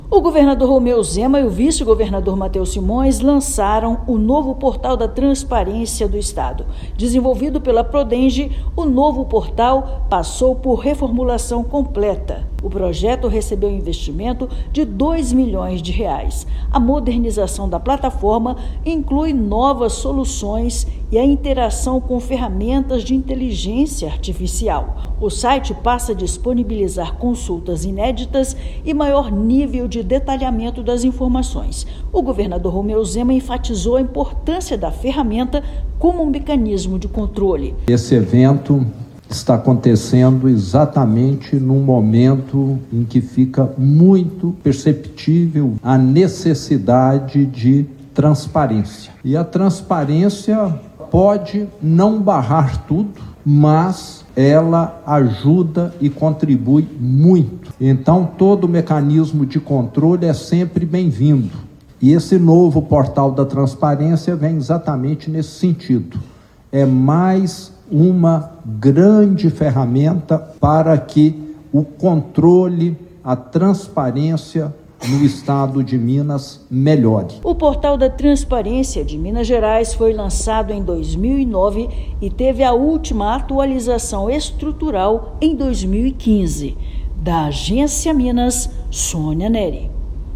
[RÁDIO] Governo de Minas lança novo Portal da Transparência com experiência mais intuitiva e centrada no cidadão